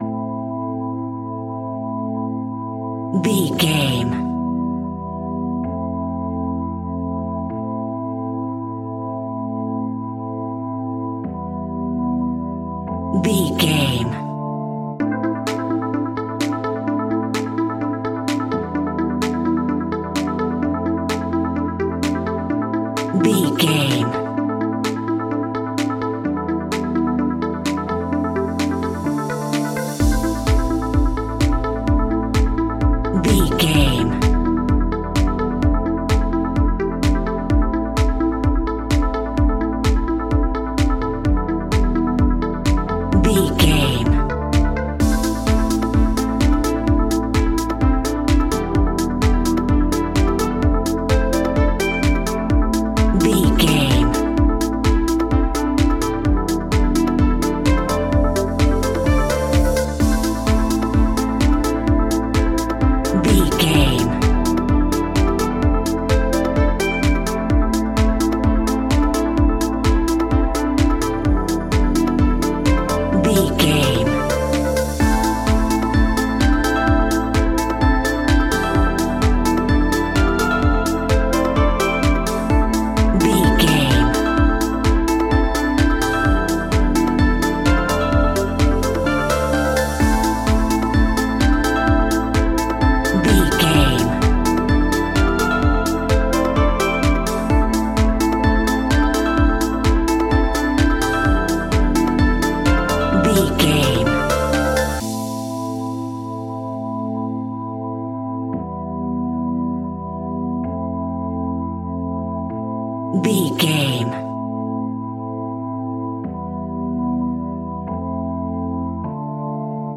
Aeolian/Minor
dark
futuristic
epic
groovy
synthesiser
drum machine
electro house
progressive house
funky house
synth leads
synth bass